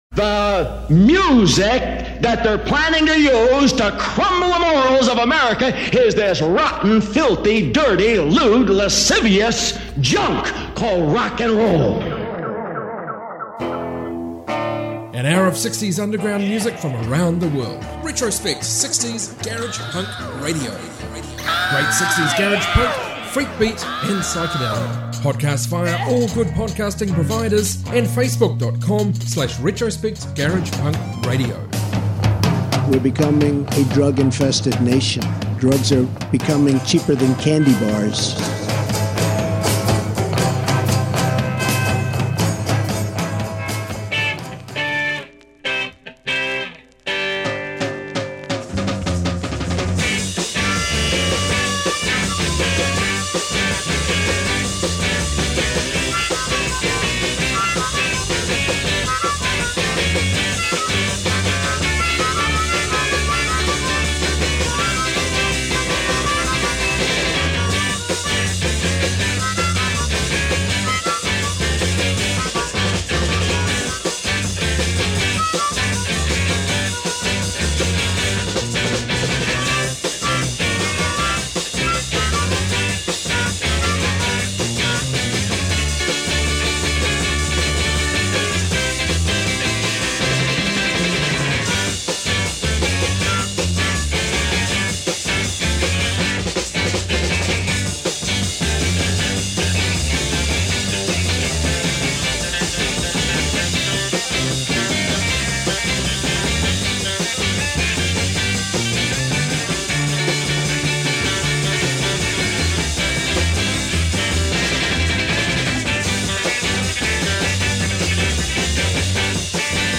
60s garage rock